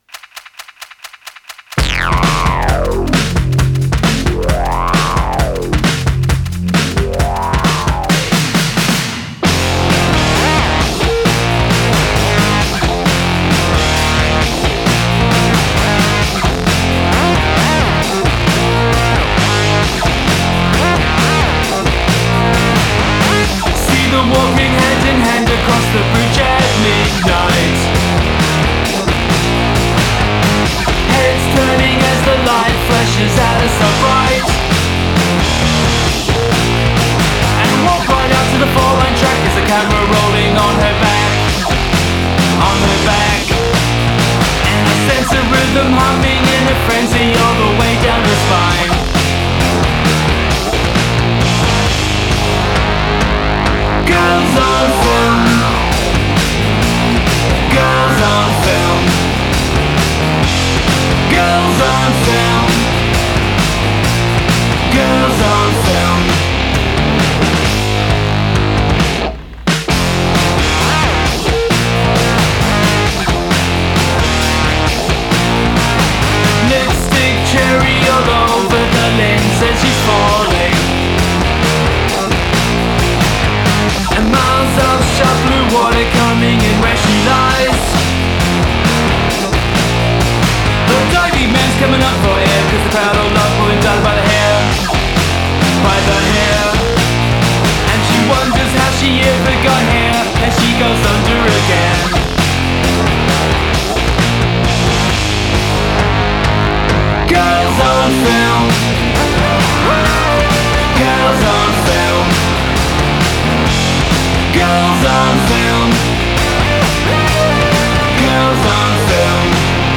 Genres : alt-rock, grunge, industrial, lo-fi, rock, synth